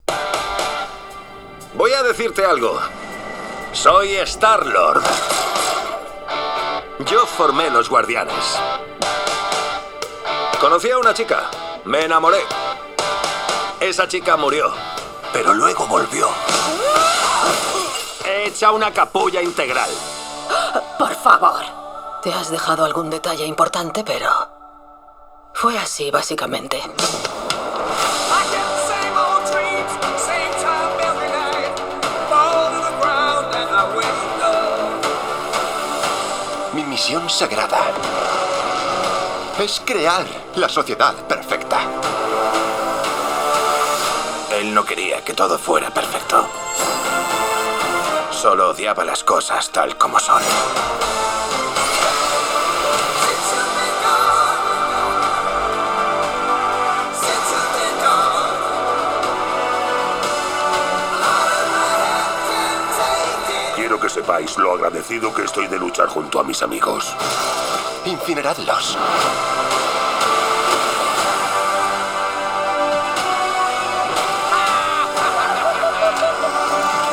El sonido integrado de este Microsoft Surface Laptop 5 sentimos que ha mejorado su calidad, aunque mantiene la configuración de 2 altavoces Omnisonic orientados hacia abajo compatibles con Dolby Atmos. Tenemos un sonido perfectamente equilibrado, alcanzando volúmenes elevados y aceptable presencia de graves, aunque lo más destacable es su claridad tal y como se puede ver en la grabación de demostración.